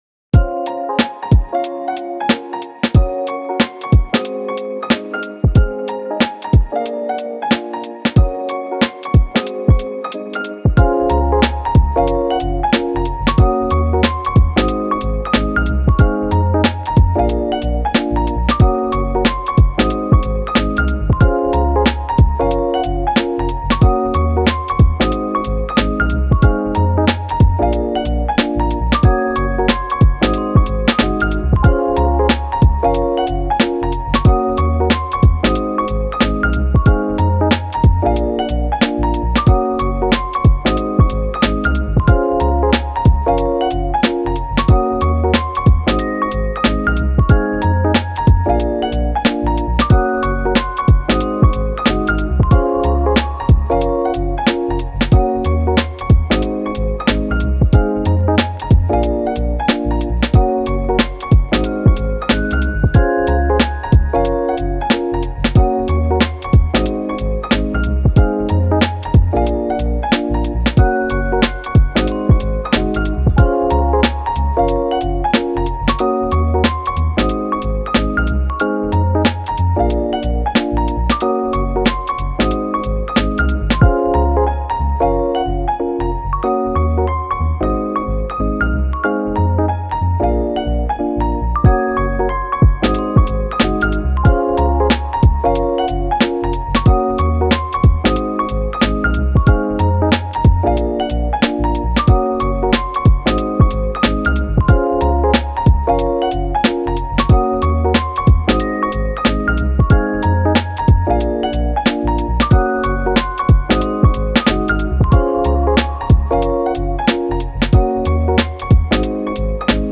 カフェミュージック チル・穏やか
チルポップ , ローファイ , 心地良い